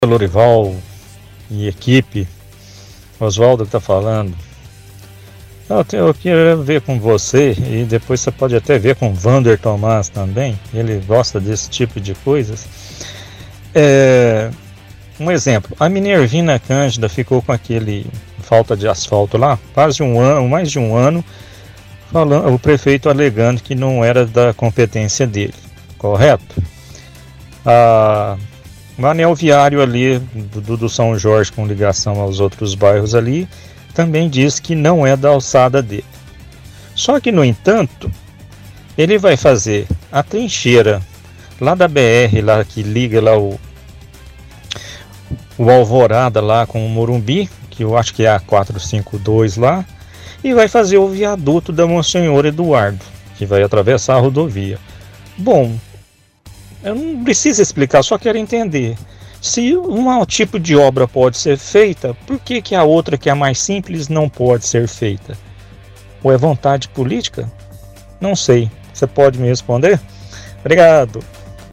-Ouvinte questiona sobre obras na cidade e cita av. Profa. Minervina Cândida e anel viário sul quando prefeito diz que não é de sua responsabilidade essas obras.